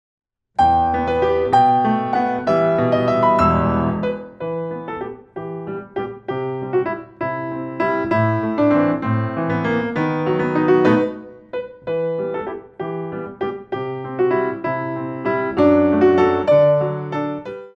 2 bar intro 6/8
32 bars